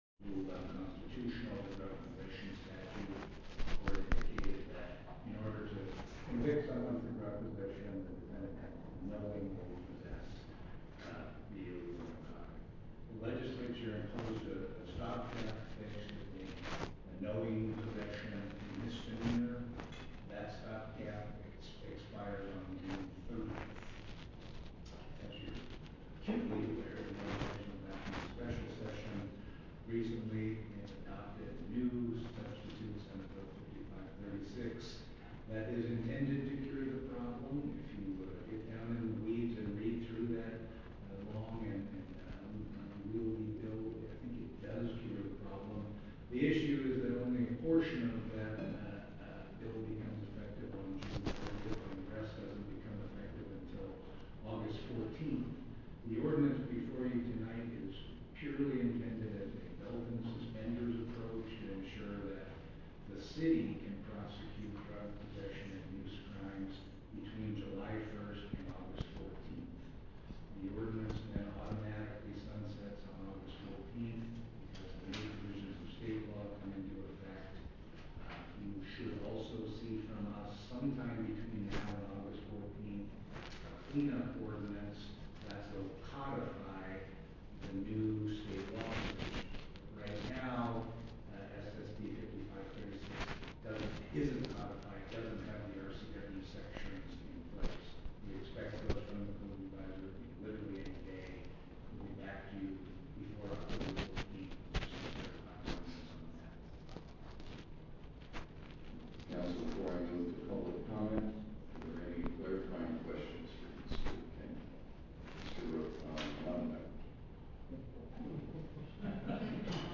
Meeting Audio - June 6, 2023 Part 1 - Technical Difficulties Brief Description - Audio courtesy of Snoqualmie Valley Record